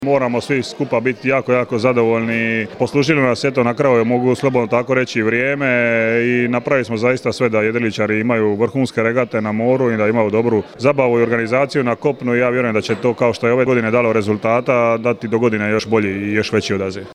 MP3 izjava